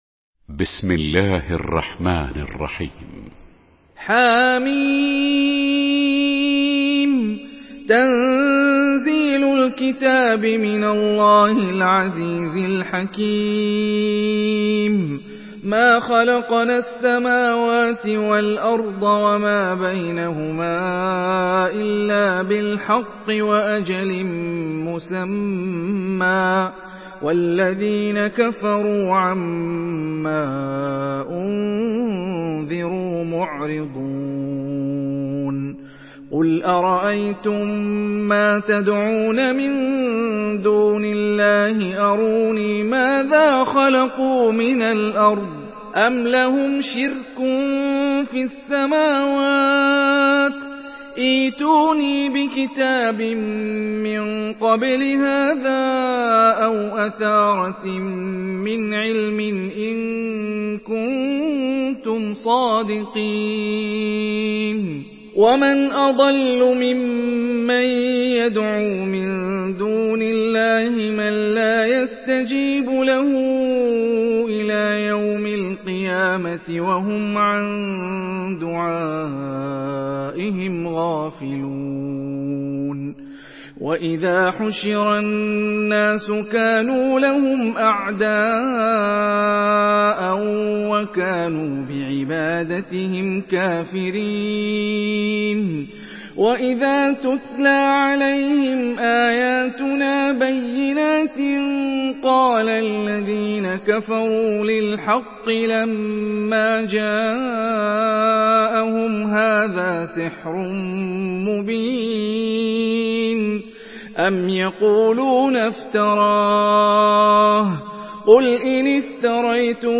Surah আল-আহক্বাফ MP3 by Hani Rifai in Hafs An Asim narration.
Murattal Hafs An Asim